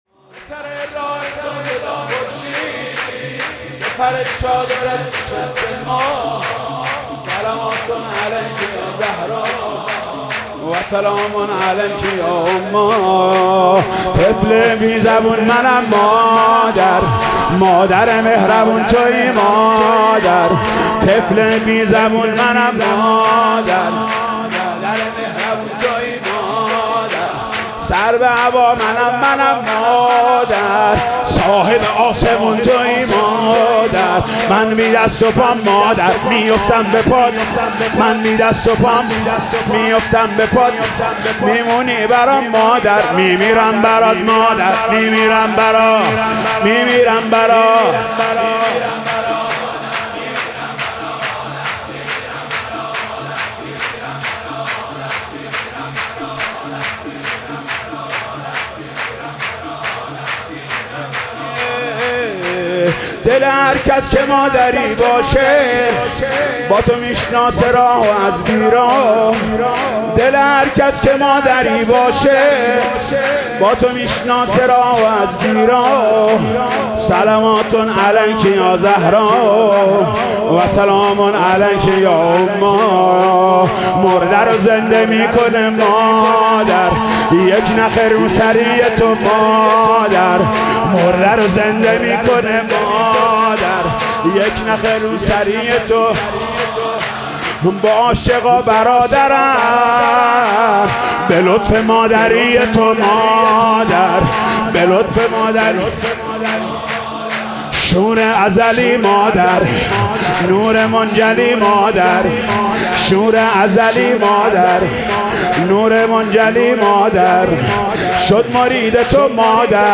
سرود مولودی شور